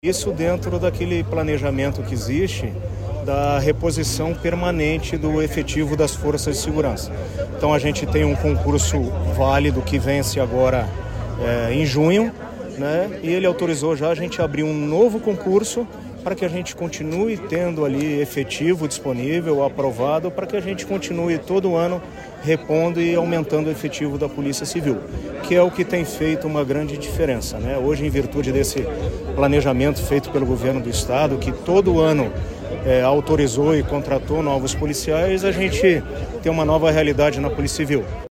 Sonora do delegado-geral da PCPR, Silvio Rockembach, sobre o novo concurso da Polícia Civil para este ano
SONORA SILVIO ROCKEMBACH - NOVO CONCURSO.mp3